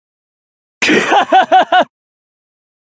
Play, download and share Kuhahahaha original sound button!!!!